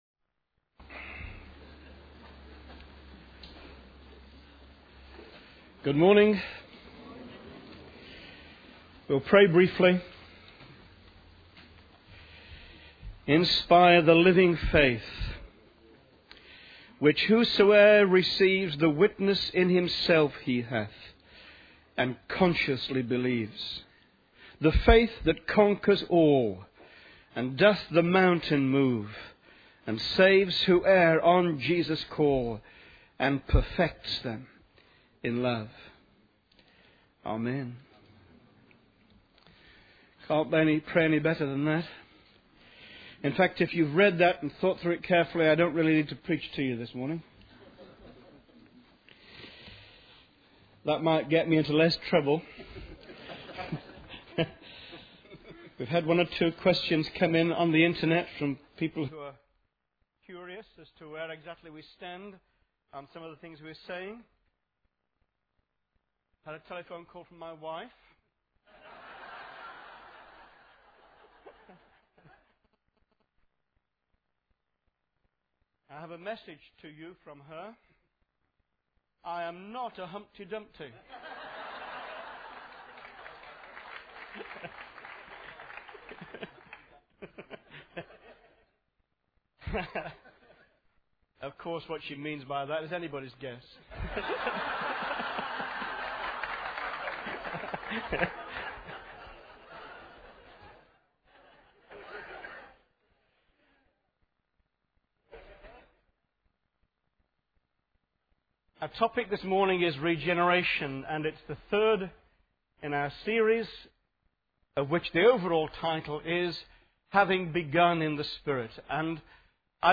Vintage sermons about authentic and Biblical Christianity.